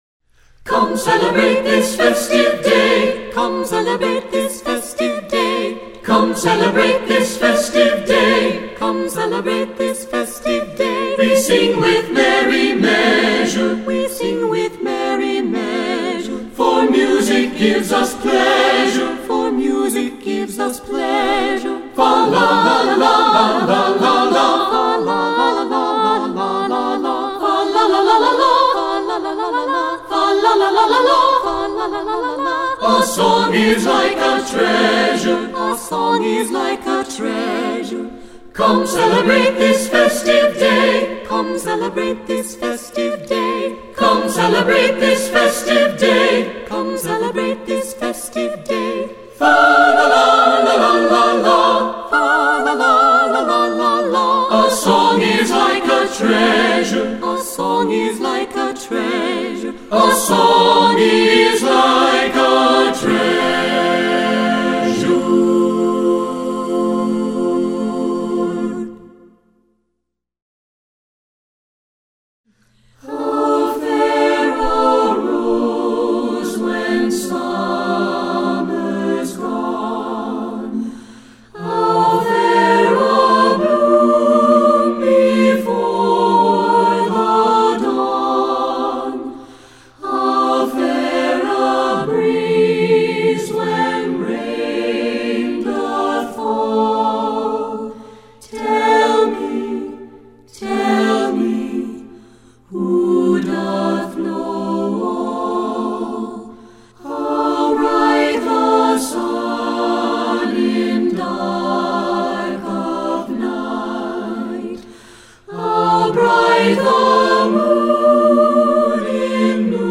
Digital sheet music for choir (3-Part Mixed, a cappella)
secular choral